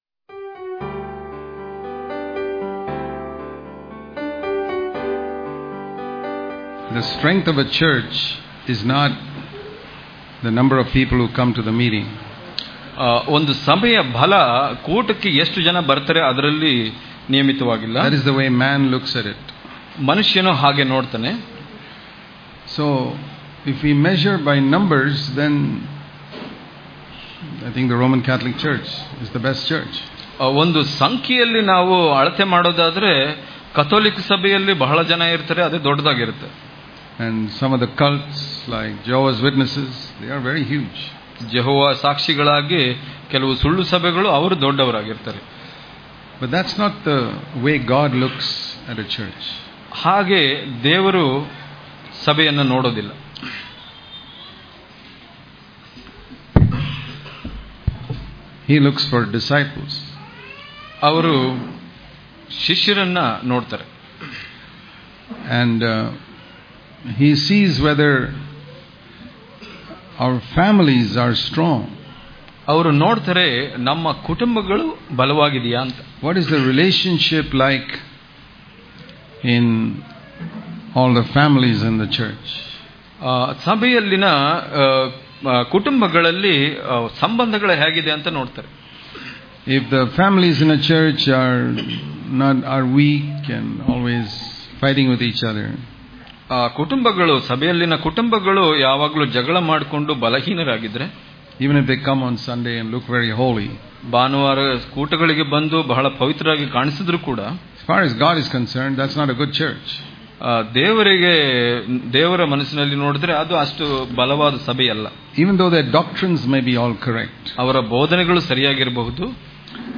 April 10 | Kannada Daily Devotion | Our Home Should Be Light In The Darkness Daily Devotions